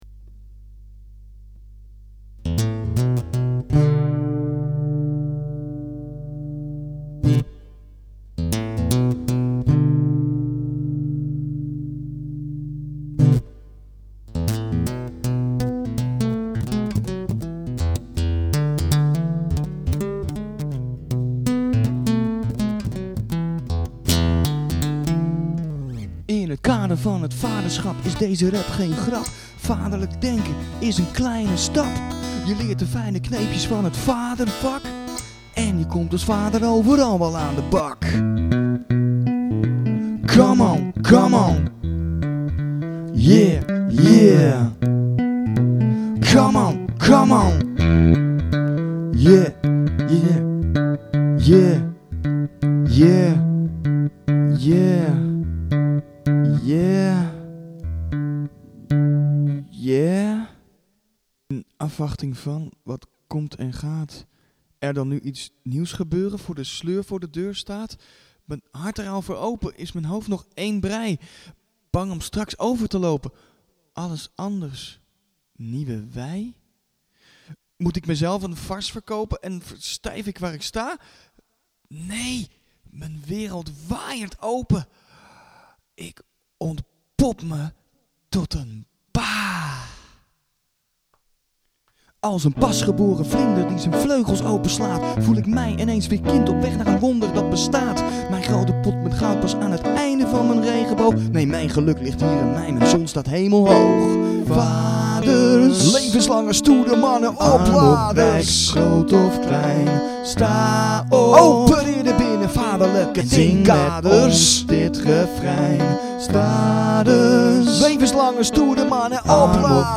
gitaar/zang
drums/zang
bas/zang